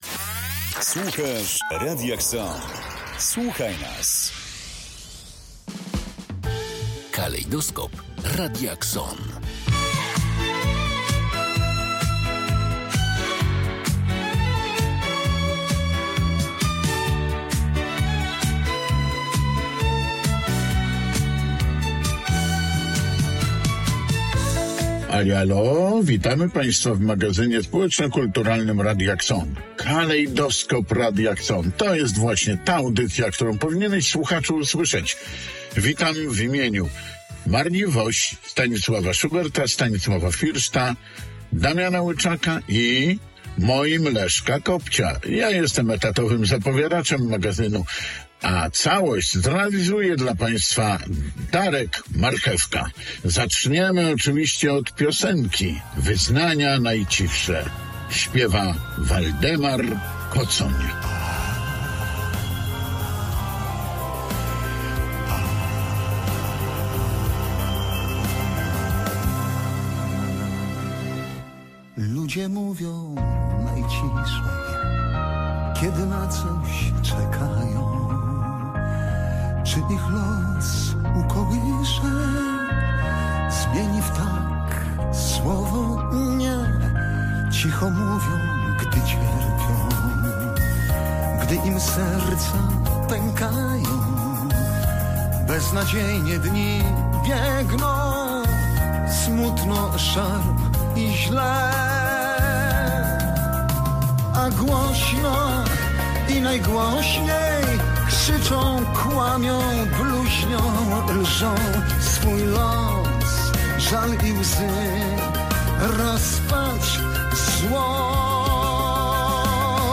Kalejdoskop Radia KSON – Magazyn Społeczno-Kulturalny (14.02.2025)